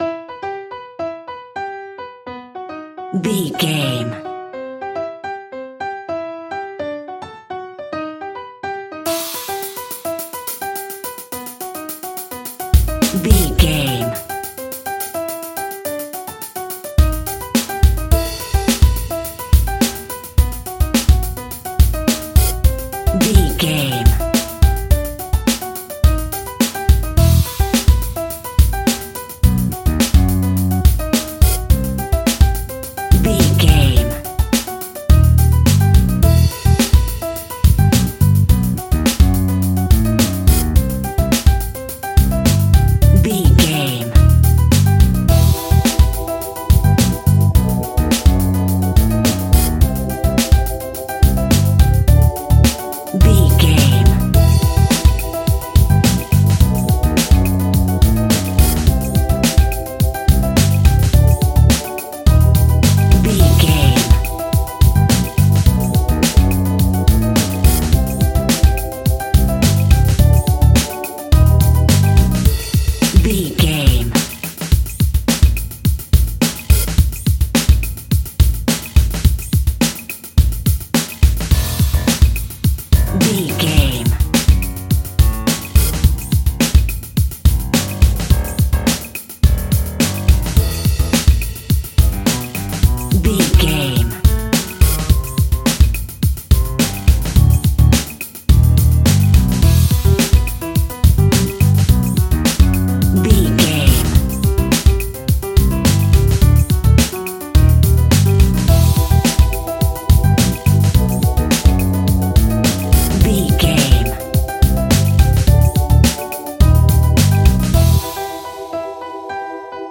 Aeolian/Minor
strings
brass
percussion